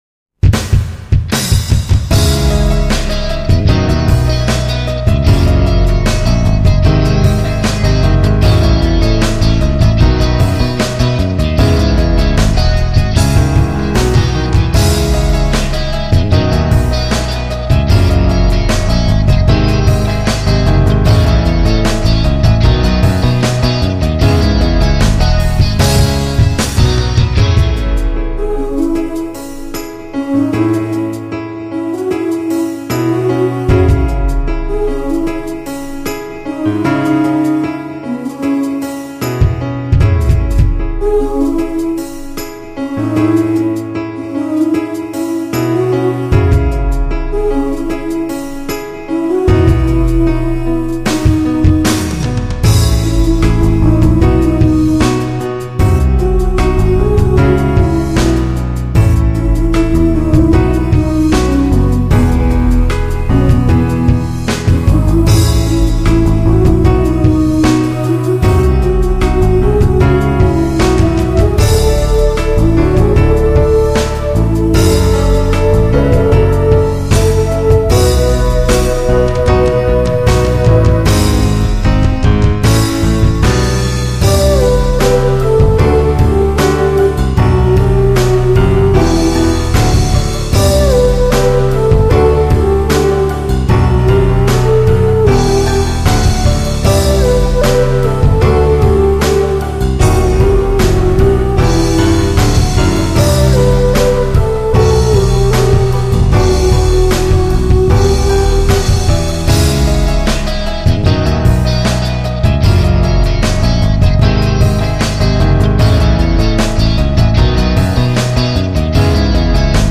2003年　1月・・・PIANOメインのJ-POPバラード